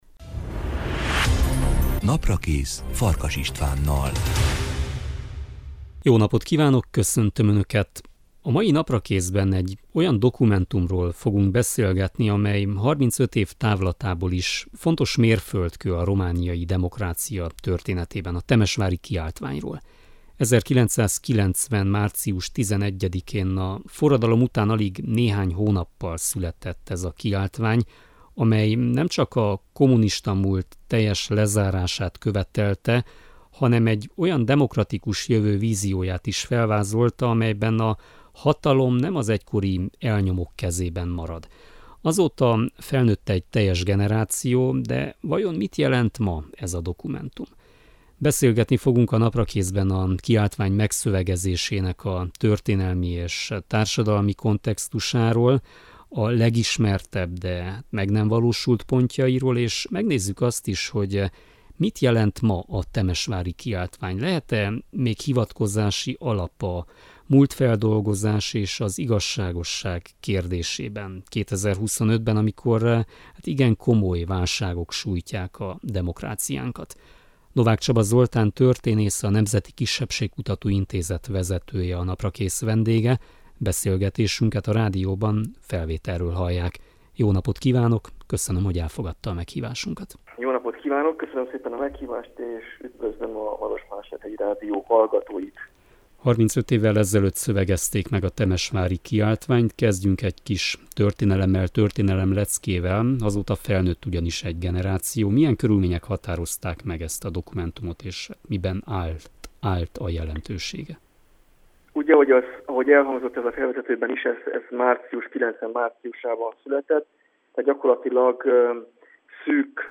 Ma egy olyan dokumentumról beszélgetünk, amely 35 év távlatából is fontos mérföldkő a romániai demokrácia történetében: a Temesvári Kiáltványról.